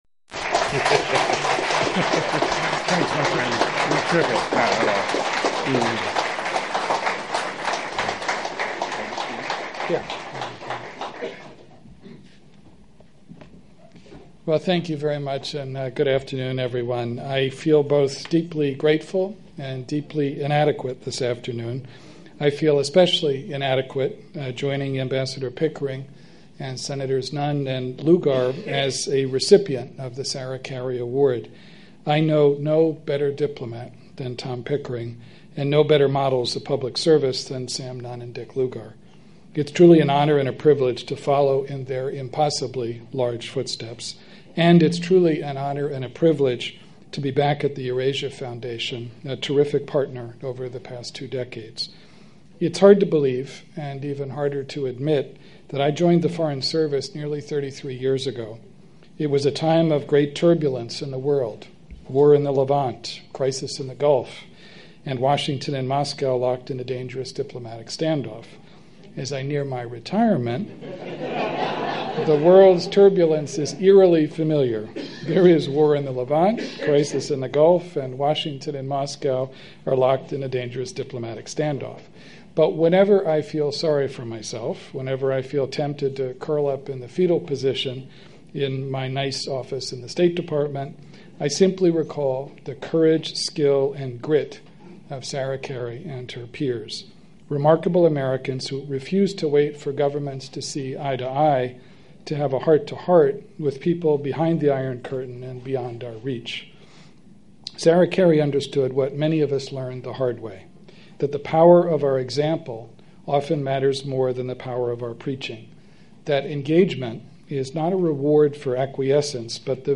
Eurasia Foundation - The 2014 Sarah Carey Forum - William Burns - Panel on US-Russia relations, Oct 21, 2014